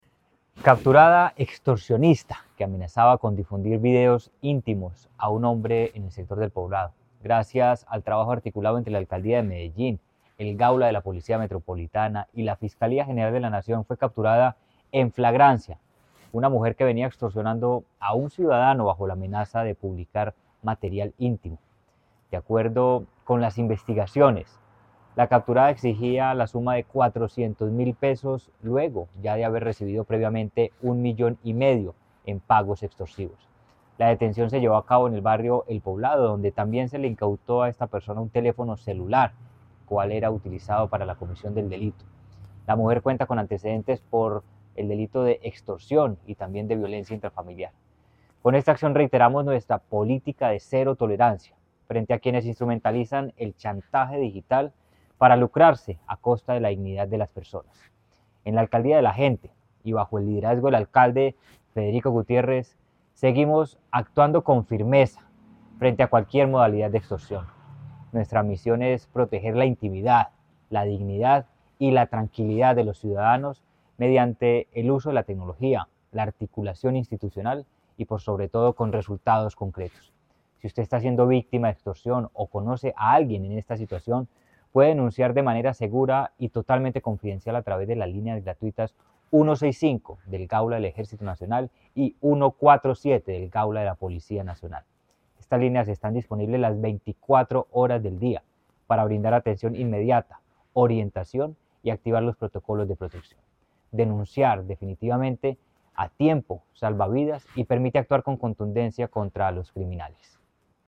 Audio-Declaraciones-del-secretario-de-Seguridad-y-convivencia-Manuel-Villa-Mejia.mp3